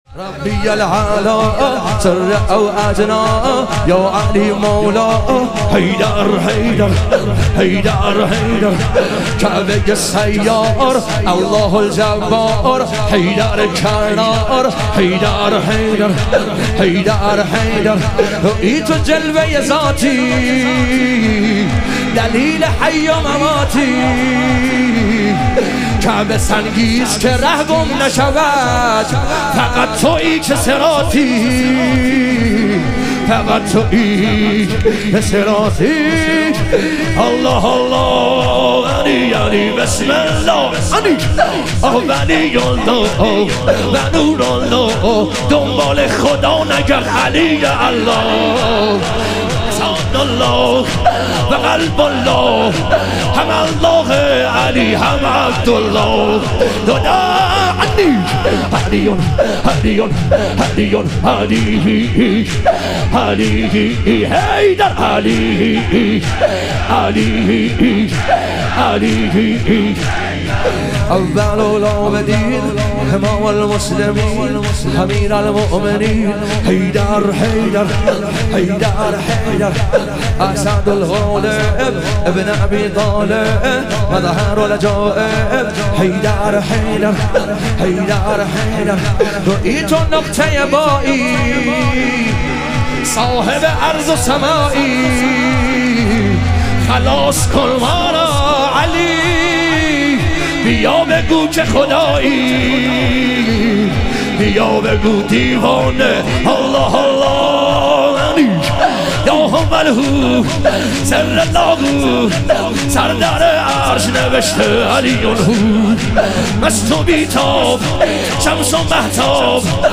ایام فاطمیه دوم - شور